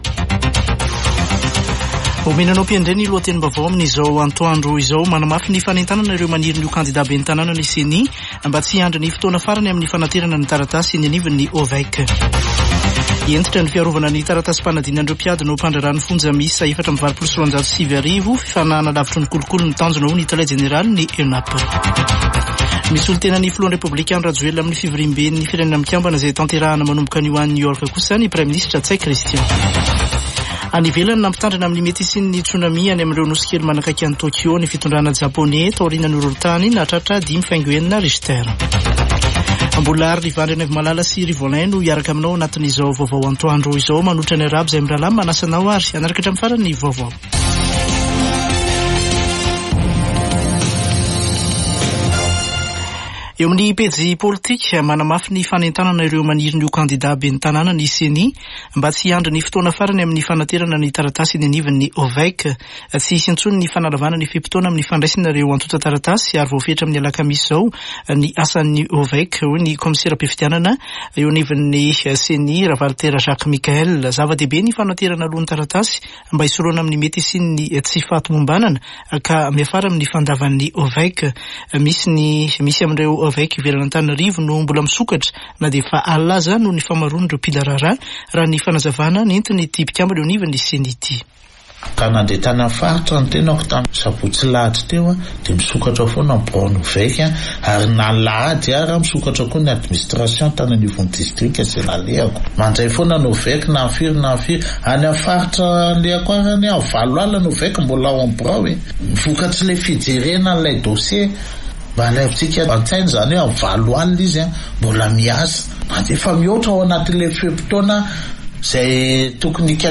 [Vaovao antoandro] Talata 24 septambra 2024